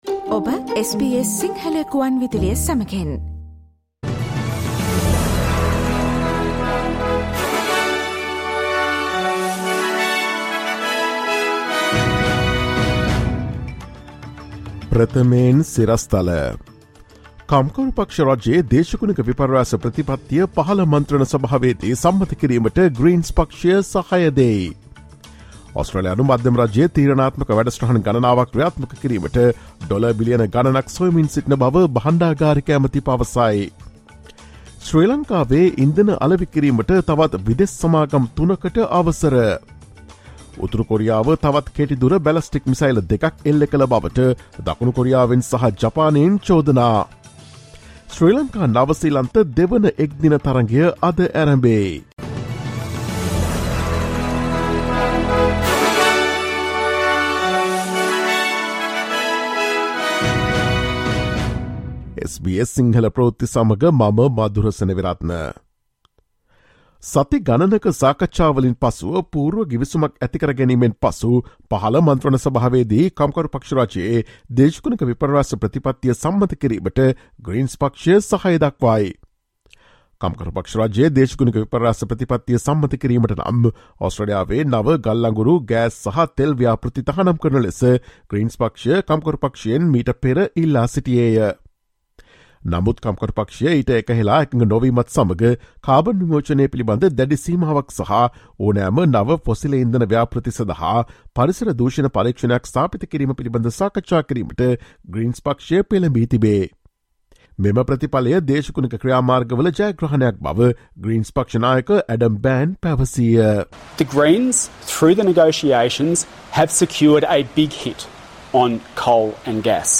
ඔස්ට්‍රේලියාවේ සහ ශ්‍රී ලංකාවේ නවතම පුවත් මෙන්ම විදෙස් පුවත් සහ ක්‍රීඩා පුවත් රැගත් SBS සිංහල සේවයේ 2023 මාර්තු 28 වන දා අඟහරුවාදා වැඩසටහනේ ප්‍රවෘත්ති ප්‍රකාශයට සවන් දෙන්න.